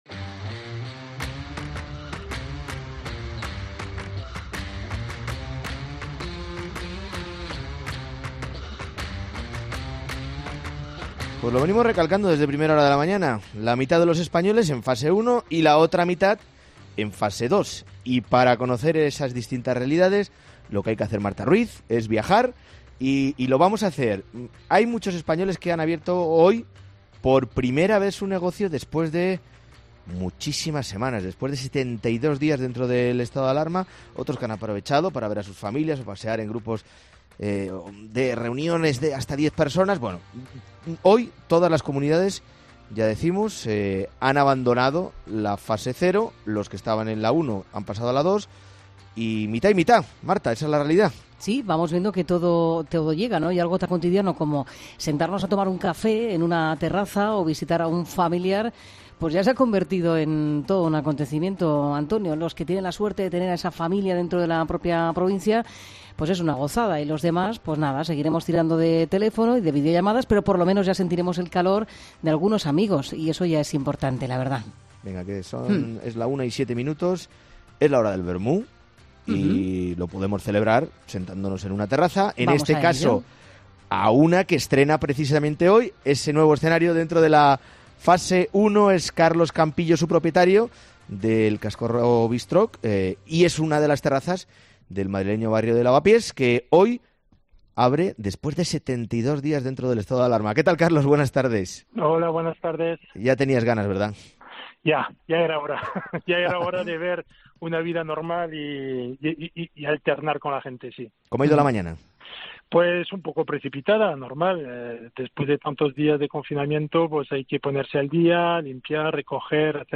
Medidía COPE se ha situado en pleno centro de Madrid, en Lavapiés, para comprobar si los madrileños se están sentando en las terrazas de los bares para inaugurar su Fase 1.